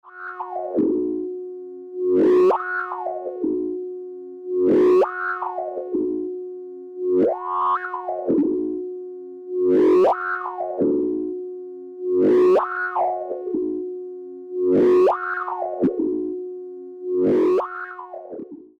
The sound could be described as very vintage, full bodied, and animated.
Unease_P9700s_Filter_ping_loop.mp3